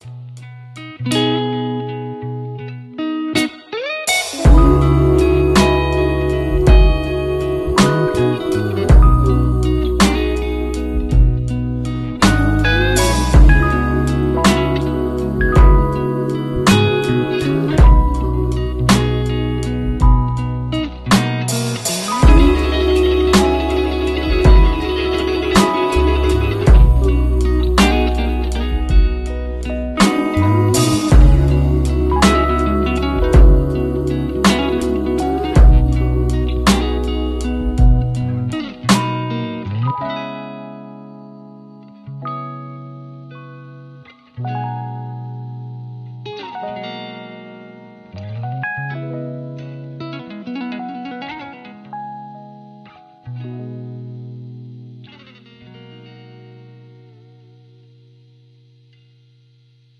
Coffee brewing, clippers buzzin the sound effects free download